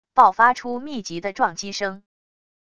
爆发出密集的撞击声wav音频